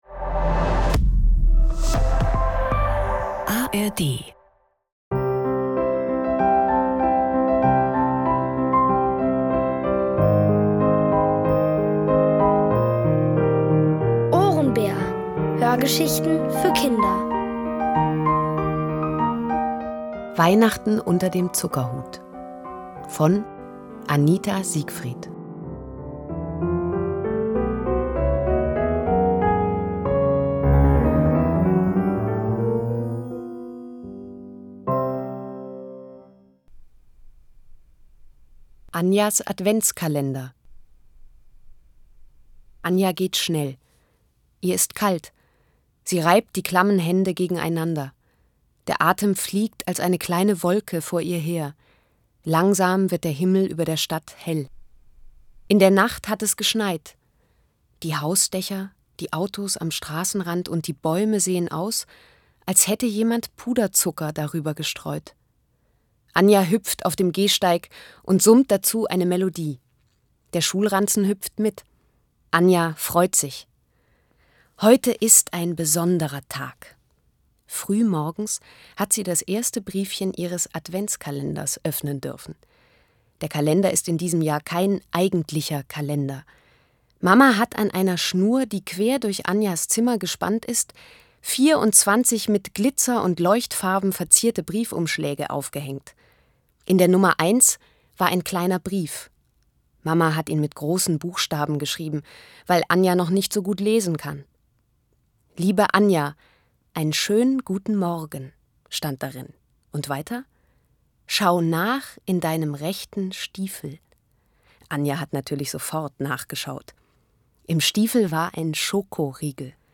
Es liest: Nina Hoss.